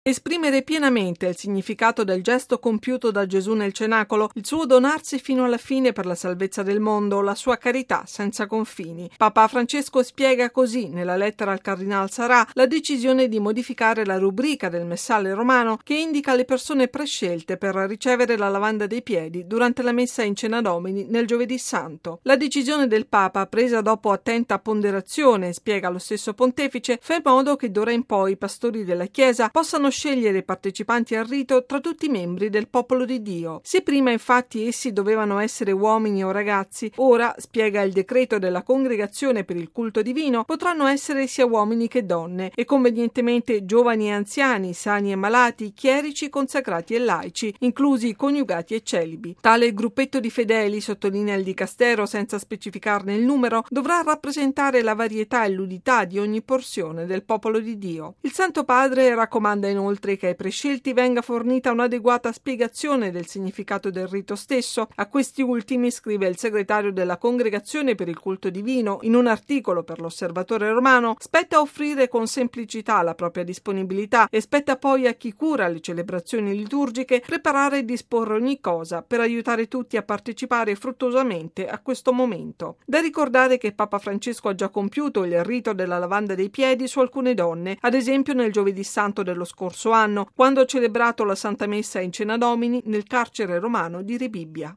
Bollettino Radiogiornale del 21/01/2016